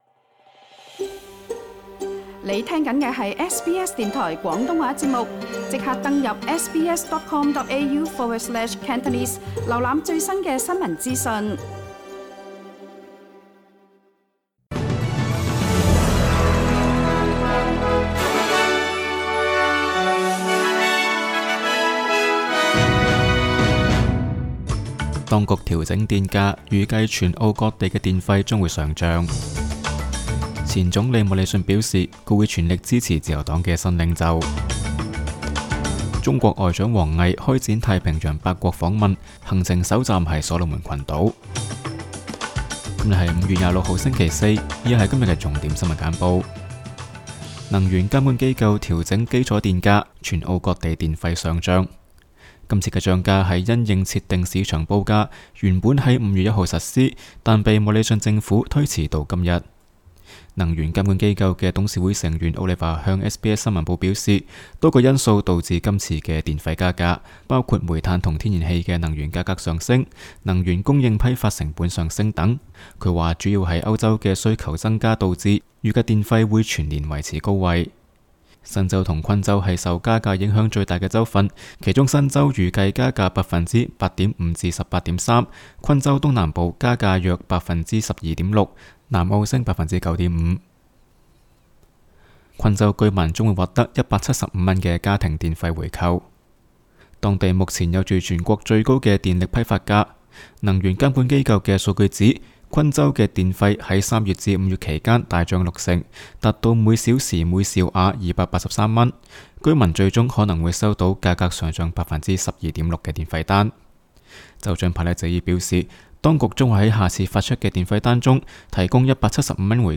SBS 新闻简报（5月26日）
SBS 廣東話節目新聞簡報 Source: SBS Cantonese